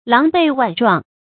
狼狈万状 láng bèi wàn zhuàng 成语解释 狼狈：窘迫的样子；万状：多种样子。